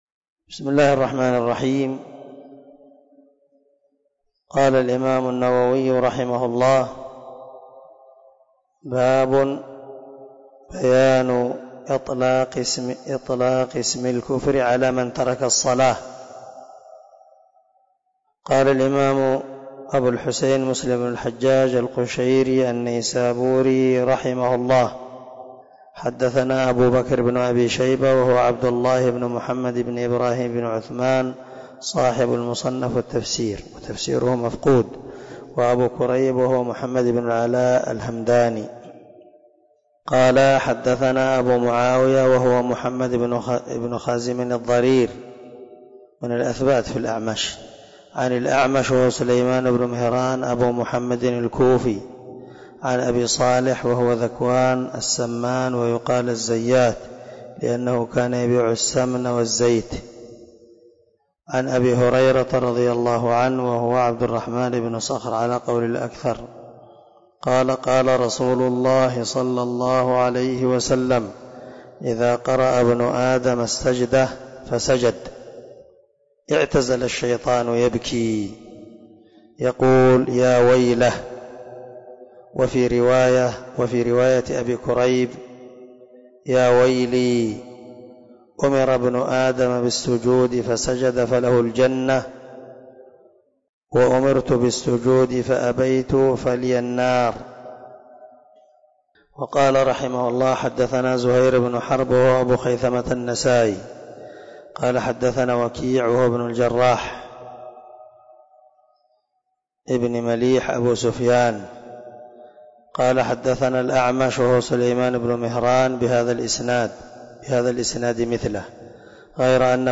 054الدرس 53 من شرح كتاب الإيمان حديث رقم ( 81 ) من صحيح مسلم